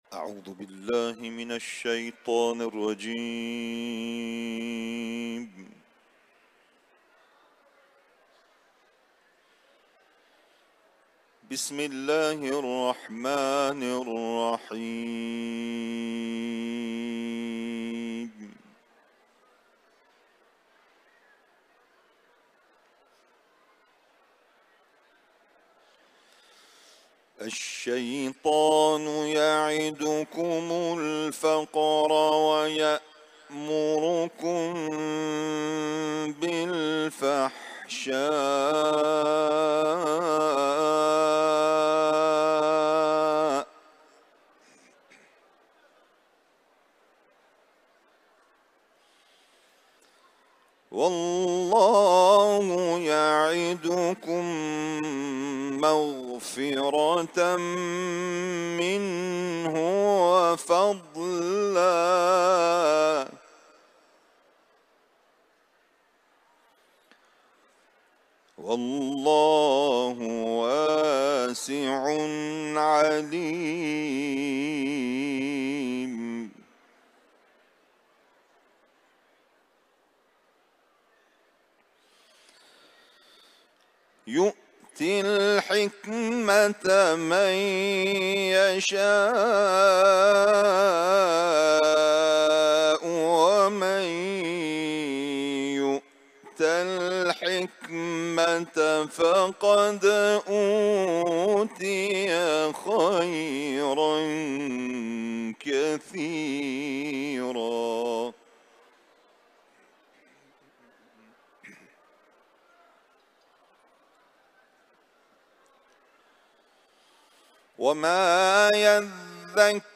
Etiketler: İranlı kâri ، Bakara Suresi ، Kuran tilaveti